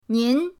nin2.mp3